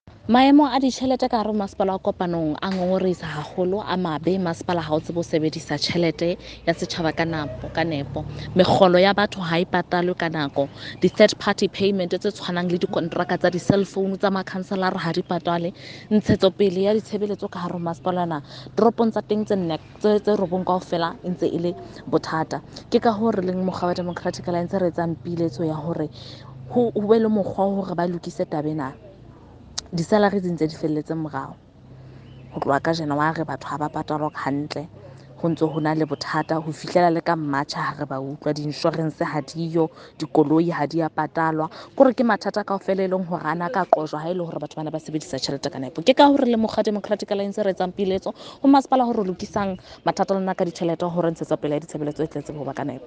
Sesotho by Karabo Khakhau MP.
SOtho-voice-Karabo-1.mp3